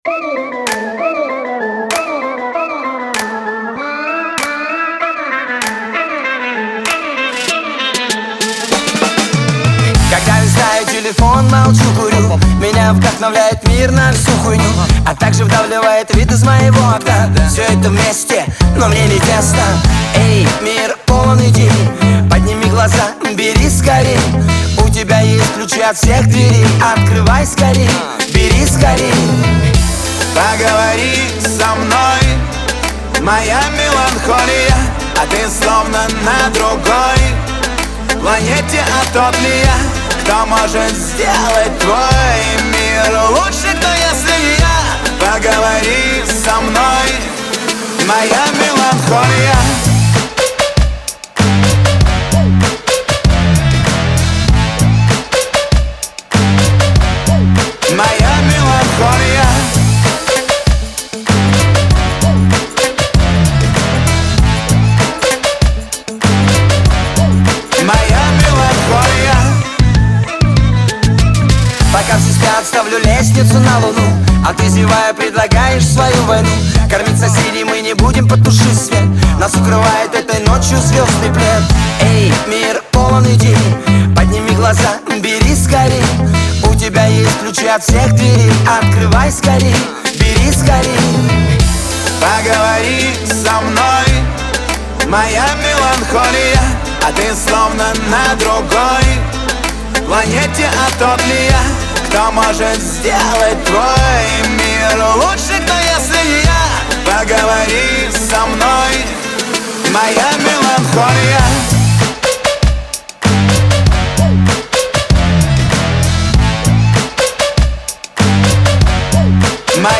это яркий пример поп-рока с меланхоличным настроением.